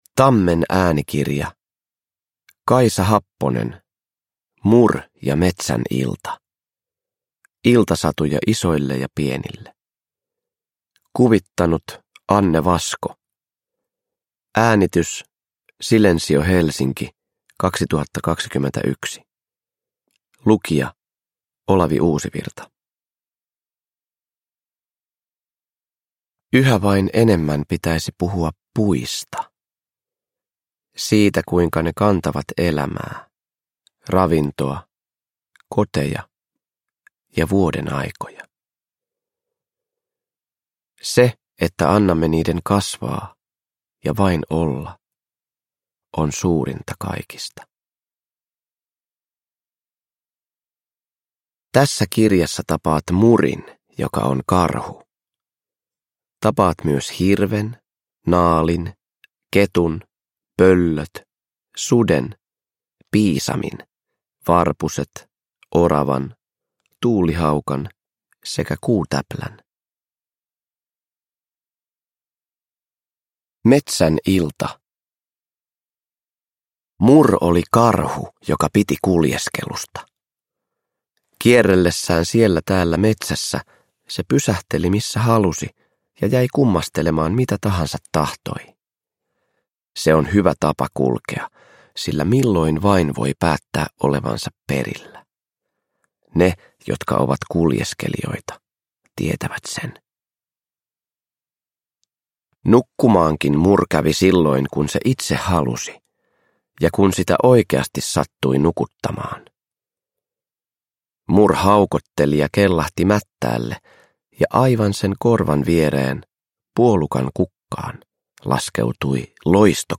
Mur ja metsän ilta – Ljudbok – Laddas ner
Uppläsare: Olavi Uusivirta